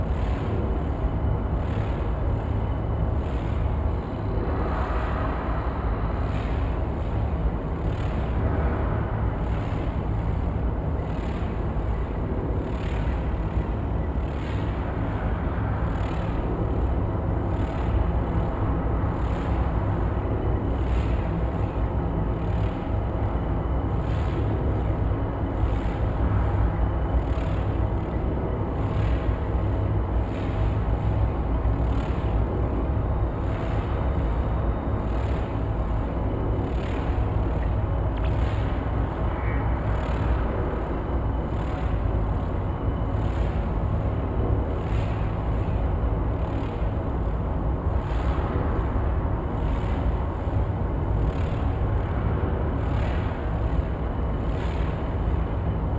7452e70b8c5ad2f7daae623e1a952eb18c9caab4 pgs / Assets / Audio / Sci-Fi Sounds / Hum and Ambience / Machine Room Loop 2.wav 9.4 MiB Raw Normal View History Unescape Escape The file is too large to be shown.
Machine Room Loop 2.wav